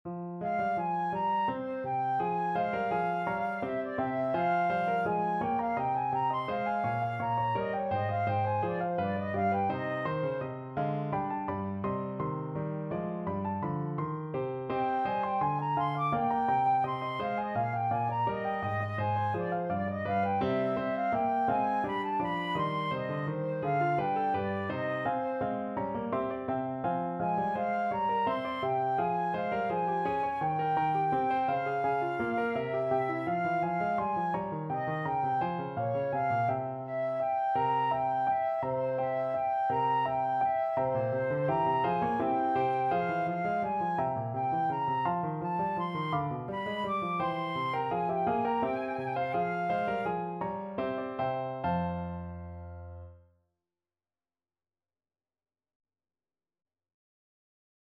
Flute
3/8 (View more 3/8 Music)
F minor (Sounding Pitch) (View more F minor Music for Flute )
Classical (View more Classical Flute Music)